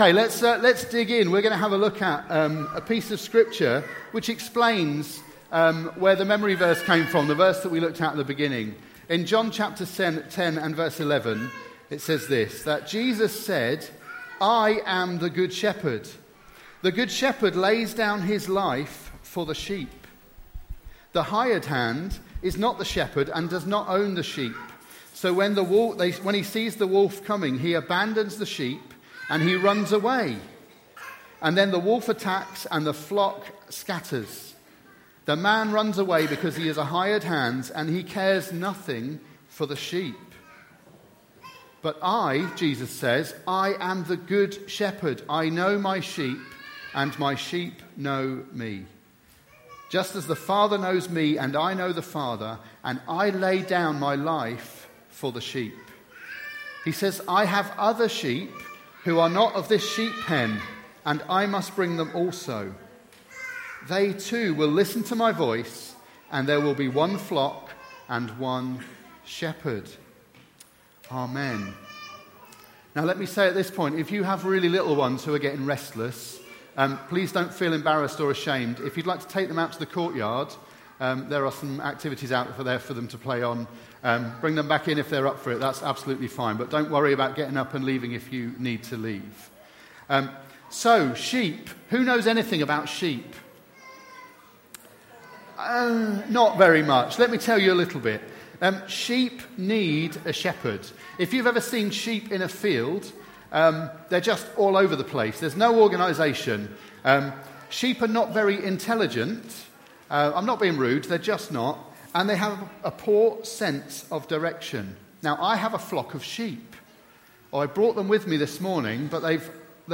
A message from the series "All Together."